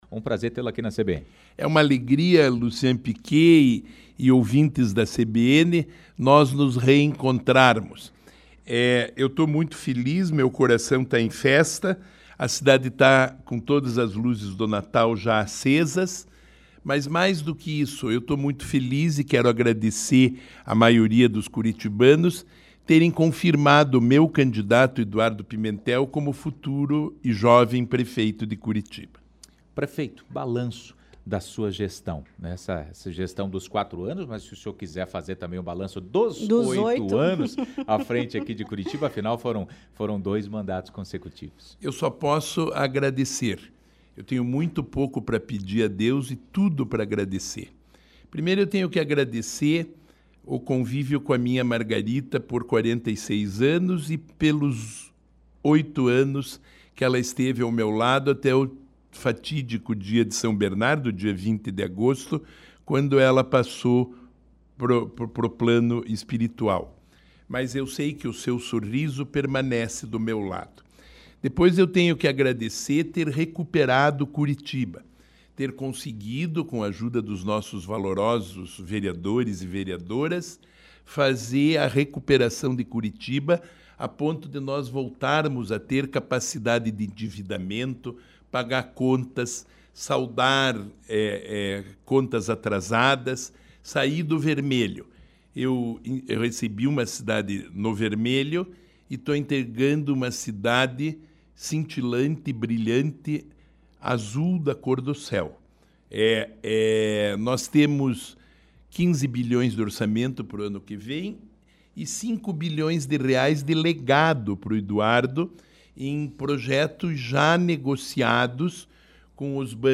Trincheiras na Linha Verde e obras do Inter 2 foram temas abordados pelo prefeito de Curitiba, Rafael Greca (PSD), em entrevista à CBN Curitiba, na manhã desta quarta-feira (27).